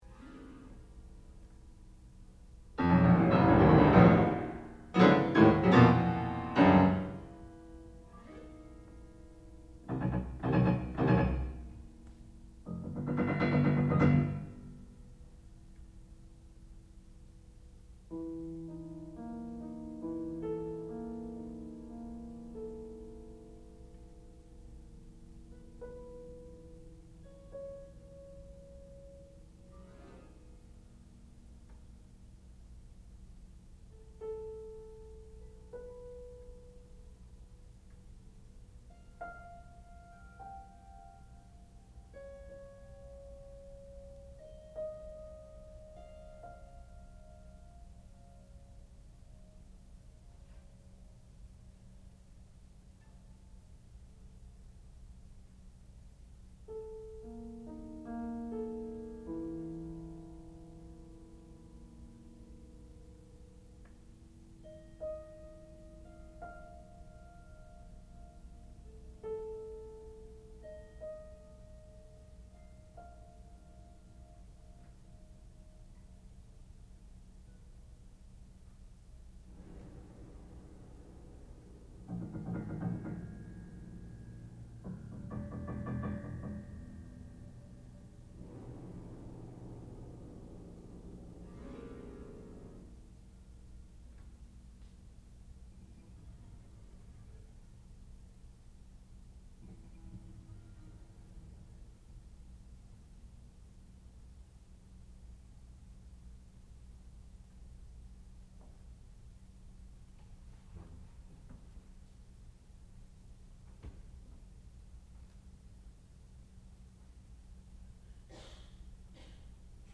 Makrokosmos, Volume II ("Twelve Fantasy Pieces after the Zodiac for Amplified Piano"), Part One
sound recording-musical
classical music
piano